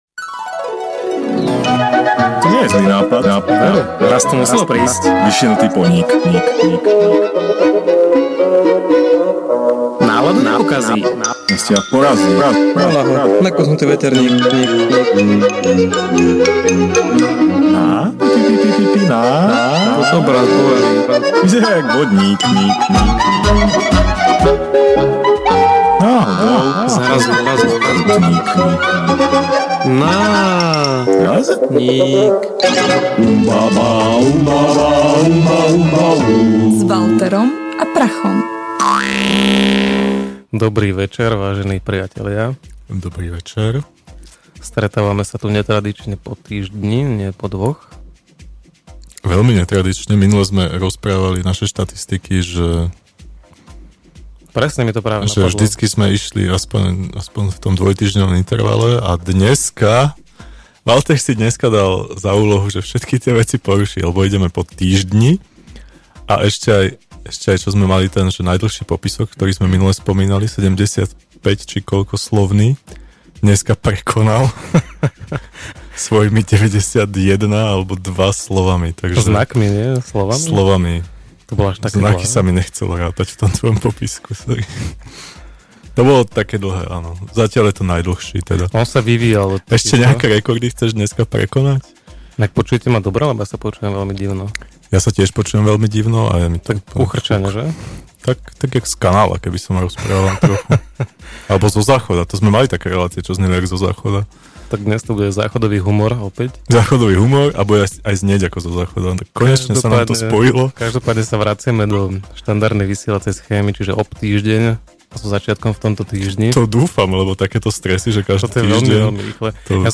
NÁRAZNÍK #58 / Malé reči - Podcast NÁRAZNÍK / Ukecaná štvrtková relácia rádia TLIS - Slovenské podcasty